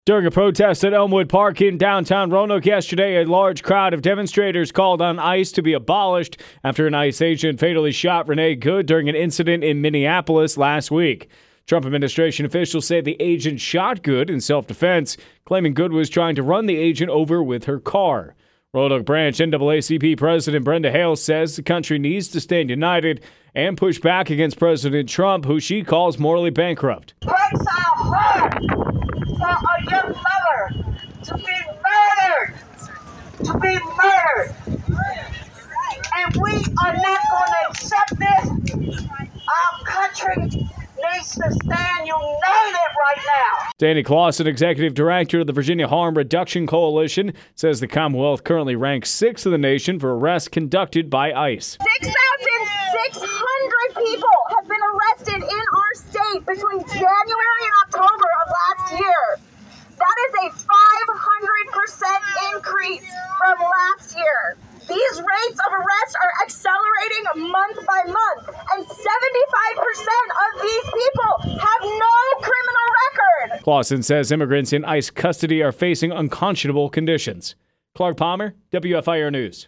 1-12-Protest-Wrap-WEB.mp3